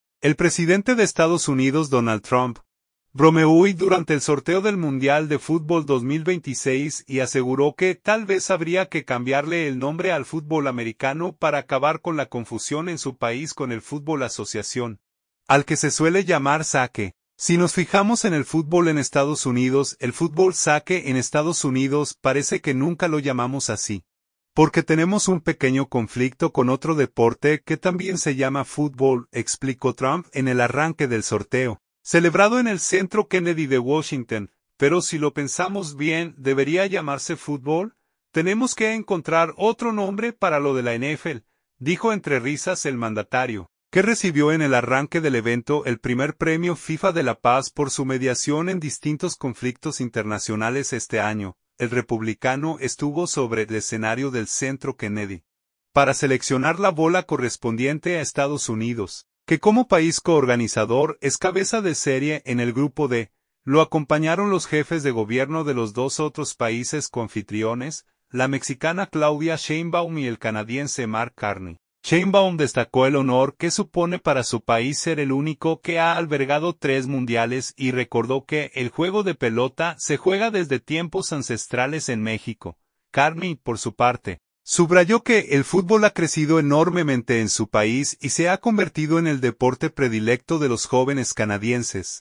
"Si nos fijamos en el fútbol en Estados Unidos, el fútbol 'soccer' en Estados Unidos, parece que nunca lo llamamos así, porque tenemos un pequeño conflicto con otro deporte que también se llama fútbol", explicó Trump en el arranque del sorteo, celebrado en el Centro Kennedy de Washington.
Tenemos que encontrar otro nombre para lo de la NFL", dijo entre risas el mandatario, que recibió en el arranque del evento el primer Premio FIFA de la Paz por su mediación en distintos conflictos internacionales este año.